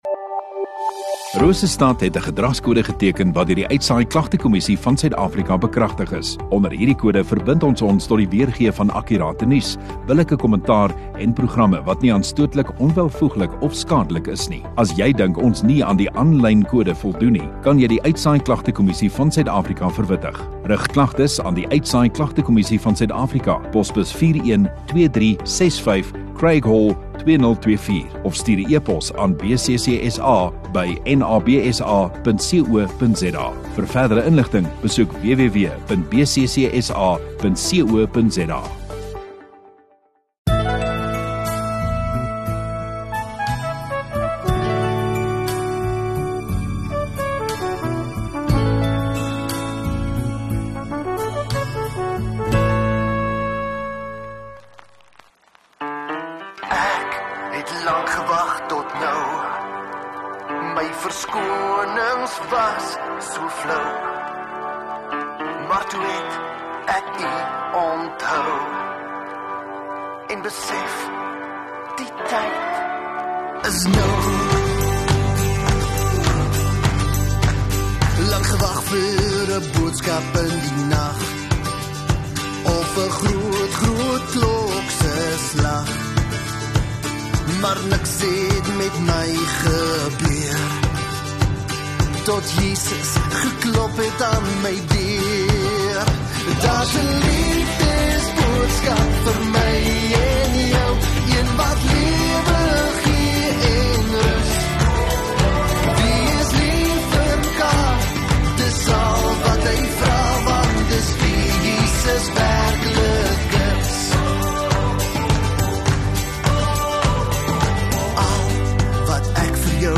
22 Feb Sondagoggend Erediens